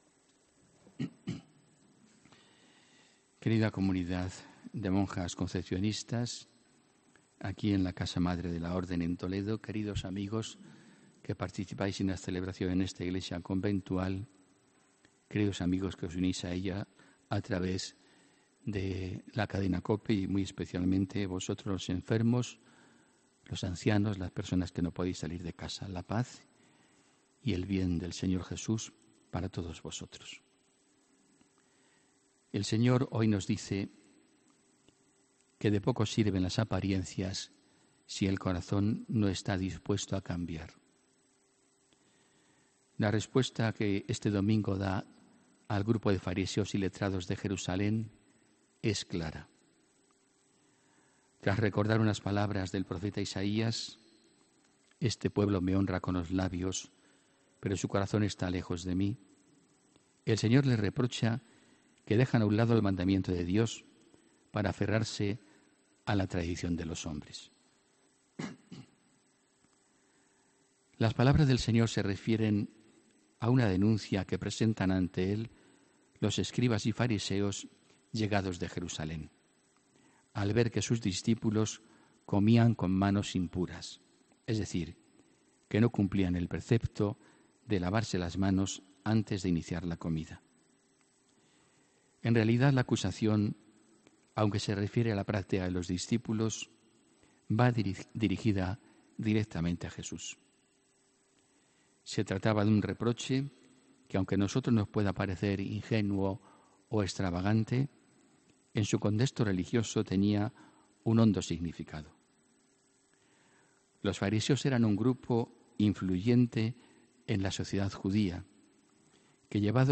HOMILÍA 2 SEPTIEMBRE 2018